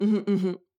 VO_ALL_Interjection_15.ogg